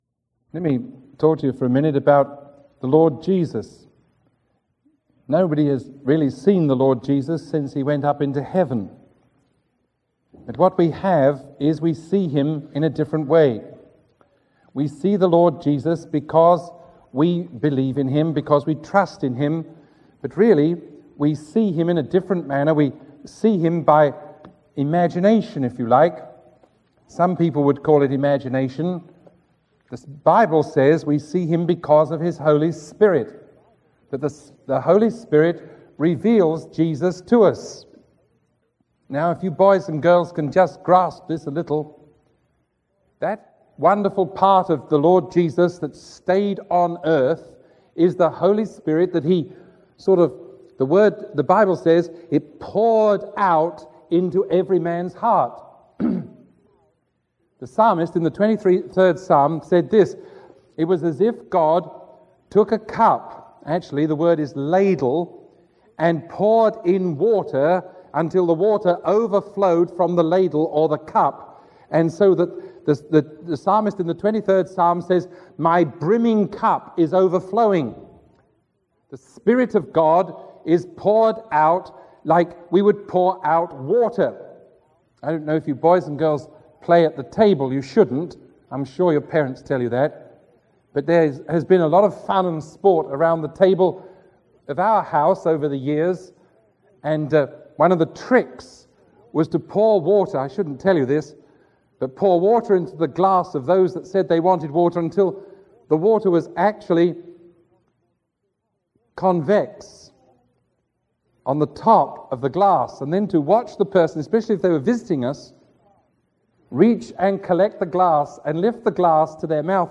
Sermon 0904A recorded on June 24